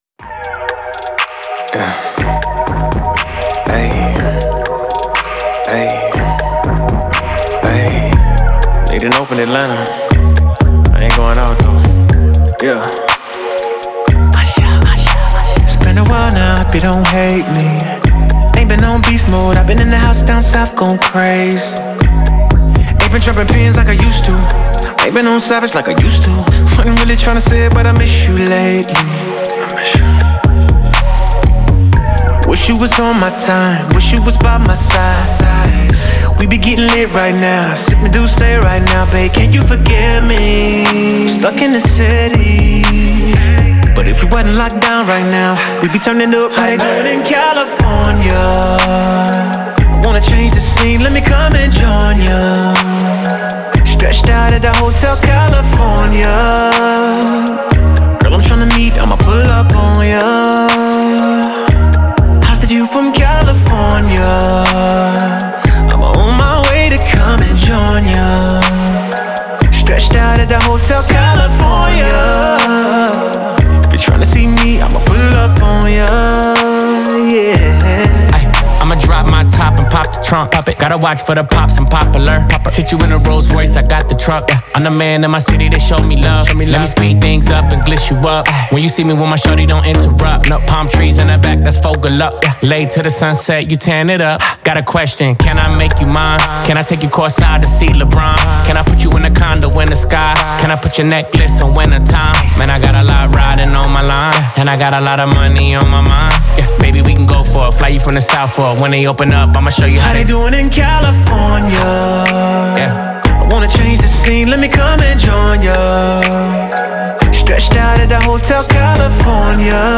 это энергичная и зажигательная песня в жанре хип-хоп и R&B